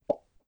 menuhit.wav